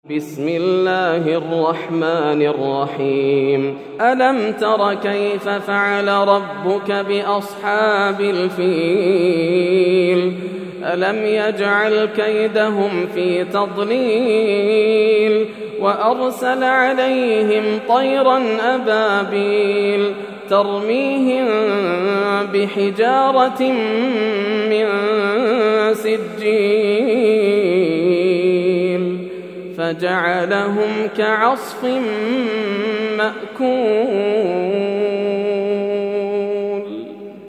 سورة الفيل > السور المكتملة > رمضان 1431هـ > التراويح - تلاوات ياسر الدوسري